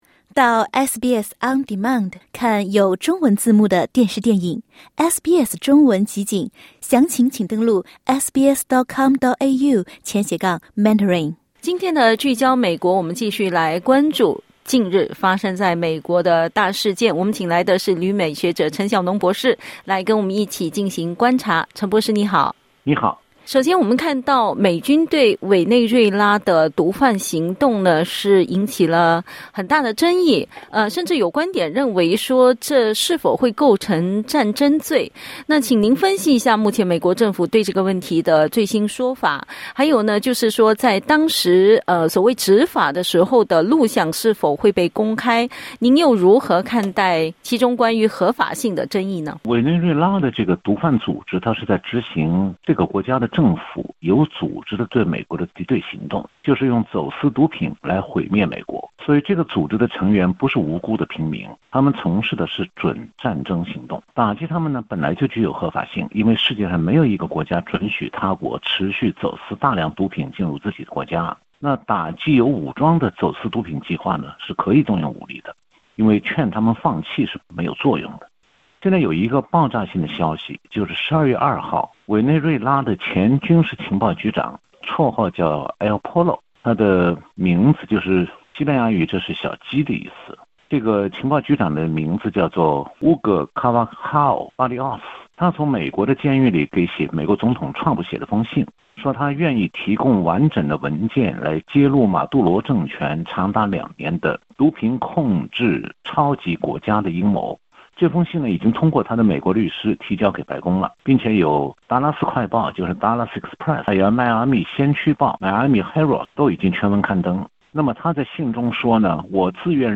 （点击音频收听详细采访） 据德国之声报道，美军9月2日对加勒比海可疑贩毒船只展开的军事行动中，第二次打击造成两名幸存者死亡。
点击音频收听详细采访 采访内容仅为嘉宾观点 欢迎下载应用程序SBS Audio，订阅Mandarin。